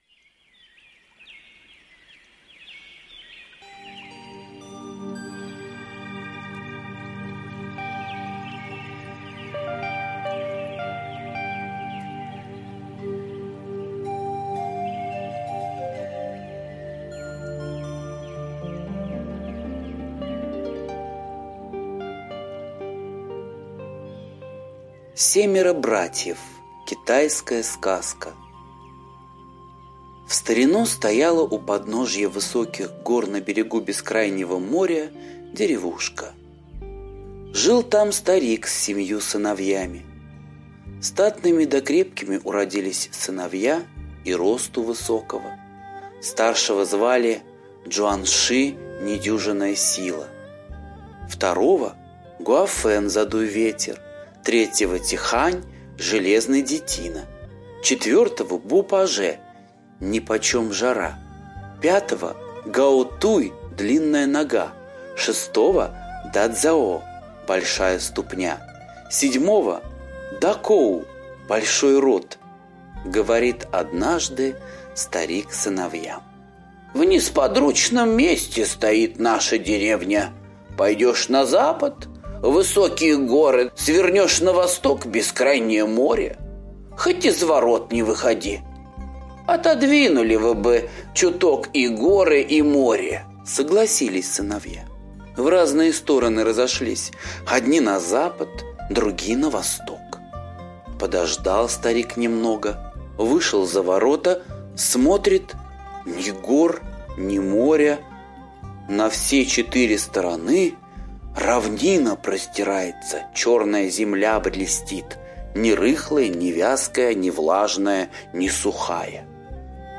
Семеро братьев - восточная аудиосказка - слушать онлайн